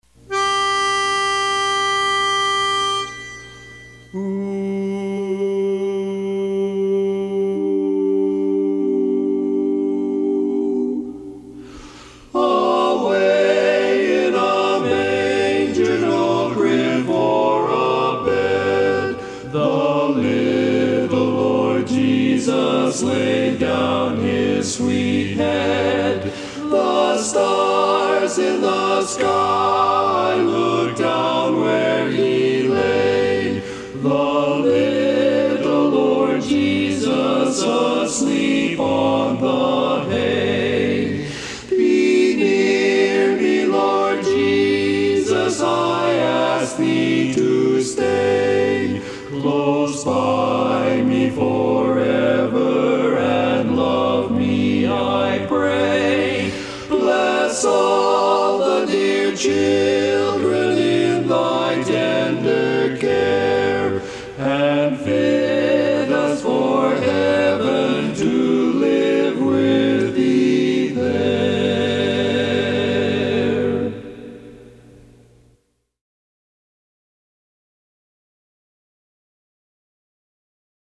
Barbershop
Tenor